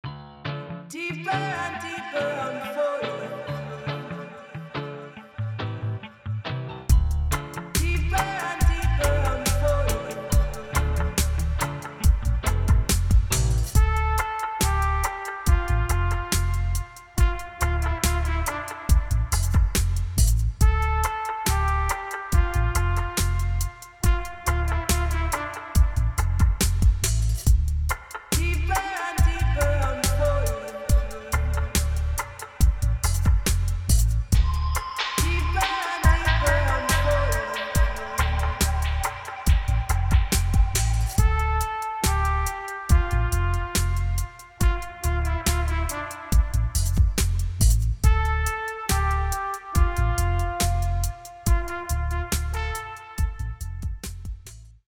Dub